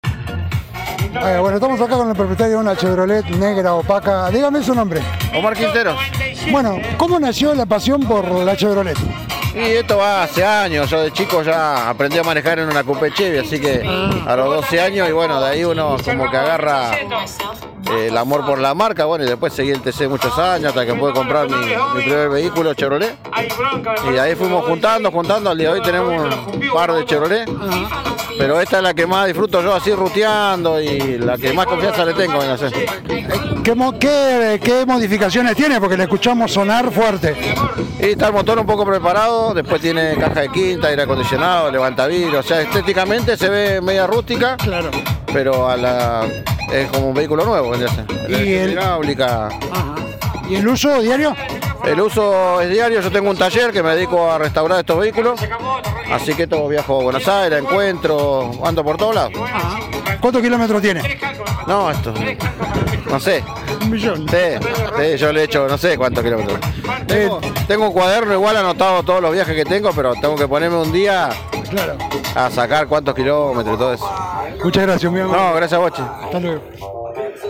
Rio Grande 19/10/2024.- Este sábado se vivió una jornada con mucho ruido de motores, escapes libres y autos que ya son clásicos. El encuentro tuvo lugar en el Parque del Centenario de nuestra ciudad y fue digno de ver.